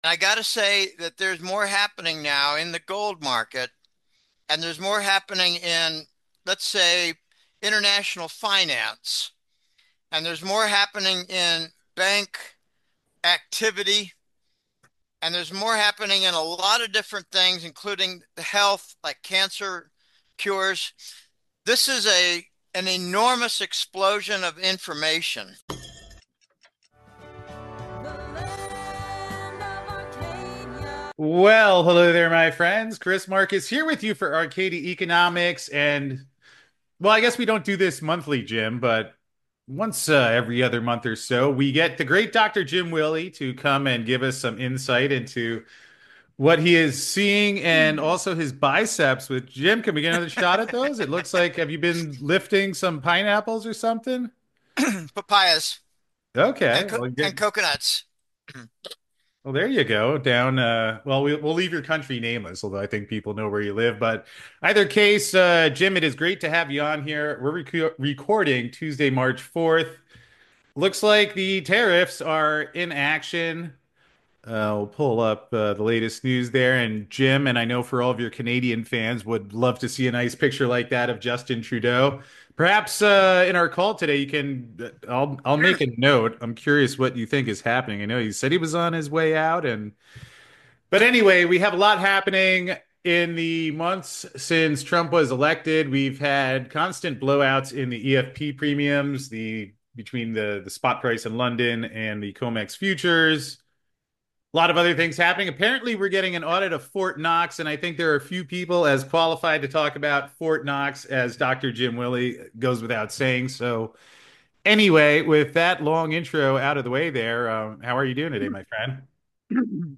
Arcadia Economics talks about how in a lively chat